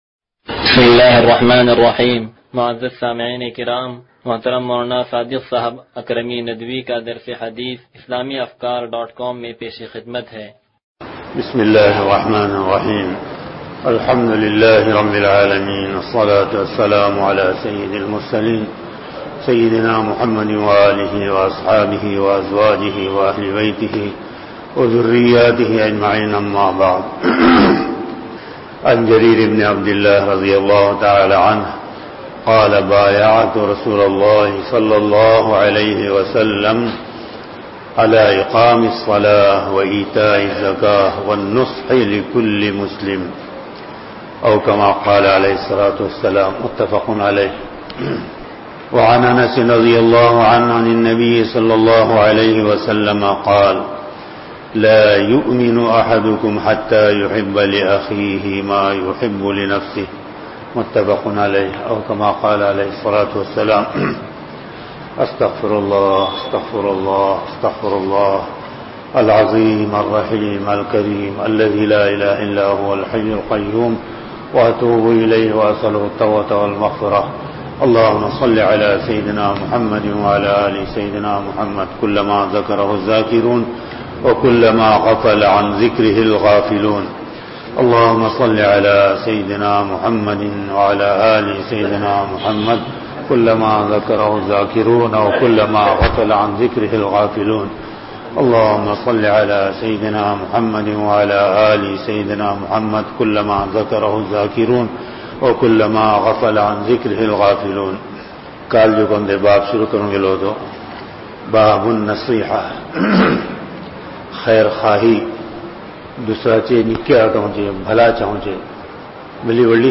درس حدیث نمبر 0197